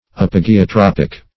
Search Result for " apogeotropic" : The Collaborative International Dictionary of English v.0.48: Apogeotropic \Ap`o*ge`o*trop"ic\, a. [Pref. apo- + Gr.